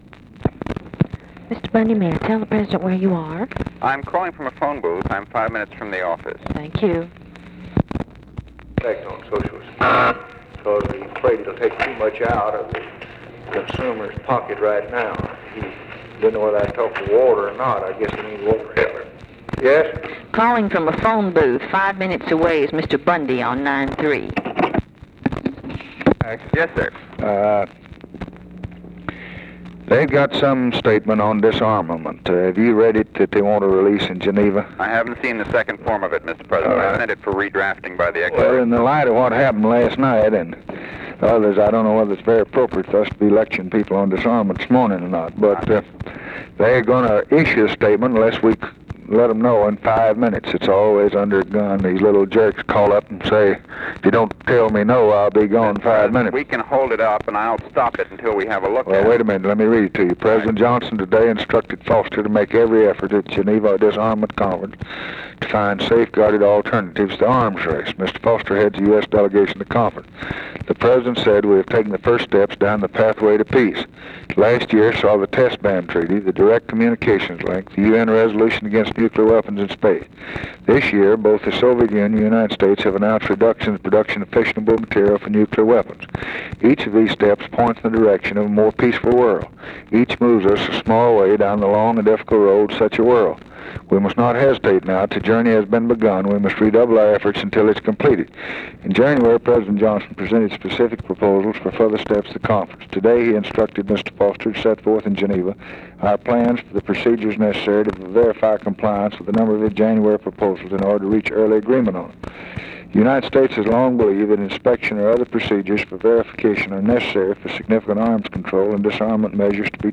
Conversation with MCGEORGE BUNDY and OFFICE CONVERSATION, June 9, 1964
Secret White House Tapes